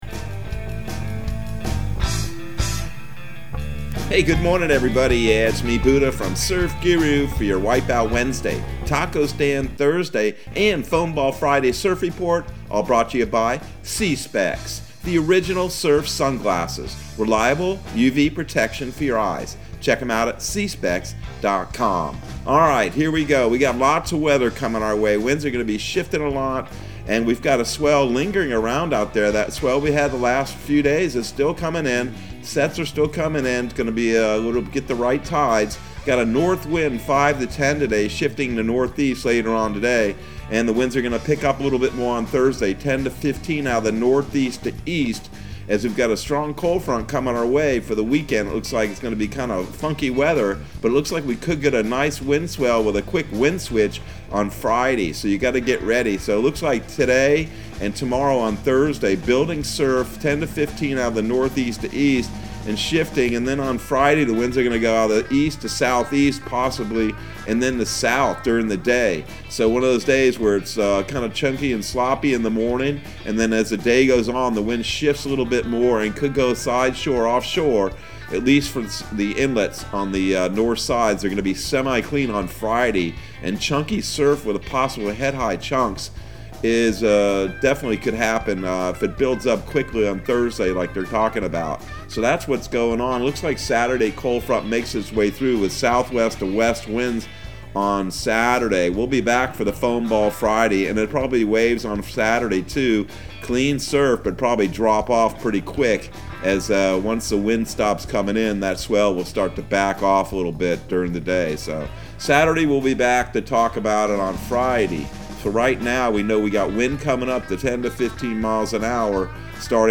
Surf Guru Surf Report and Forecast 11/03/2021 Audio surf report and surf forecast on November 03 for Central Florida and the Southeast.